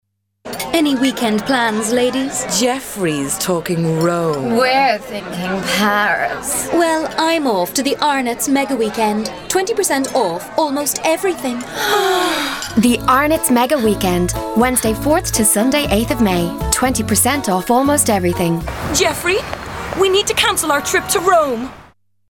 Husky tones, gravelly voice, deep timber, mature, baritone, sexy
Sprechprobe: eLearning (Muttersprache):